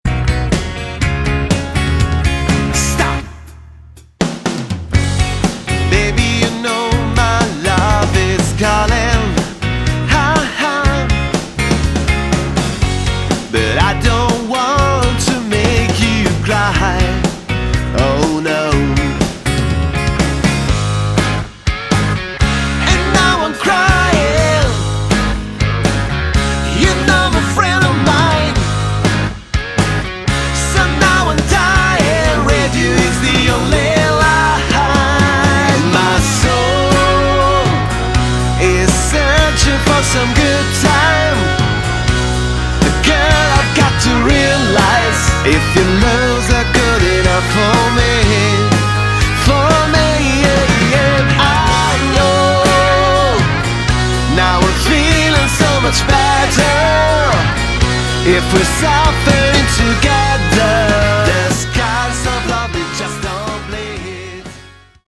Category: Hard Rock
vocals
guitar, piano
bass
drums